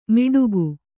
♪ miḍugu